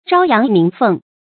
朝陽鳴鳳 注音： ㄓㄠ ㄧㄤˊ ㄇㄧㄥˊ ㄈㄥˋ 讀音讀法： 意思解釋： 比喻品德出眾、正直敢諫之人。